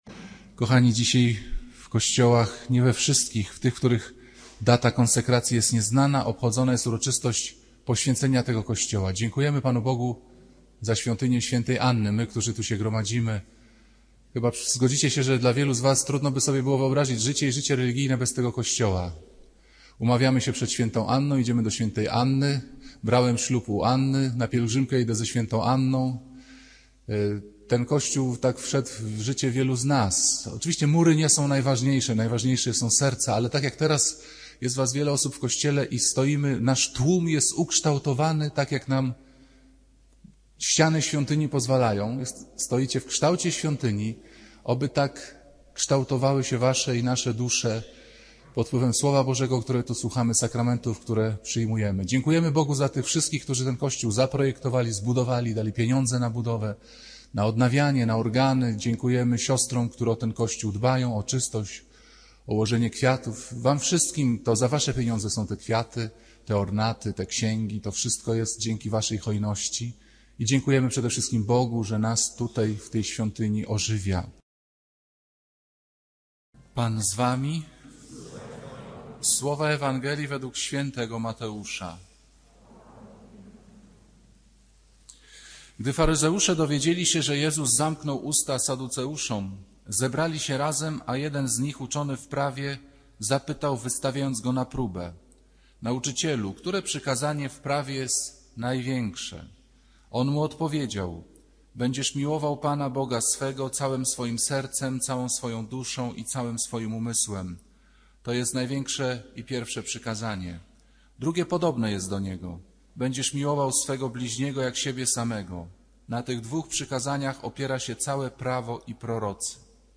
Kazanie ks. Piotra Pawlukiewicza porusza temat osobistej relacji z Bogiem. Ksiądz wskazuje na głęboki sens słowa „mój”, które odnosi się nie tylko do bliskich osób, ale także do Pana Boga.